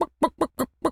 chicken_cluck_bwak_seq_15.wav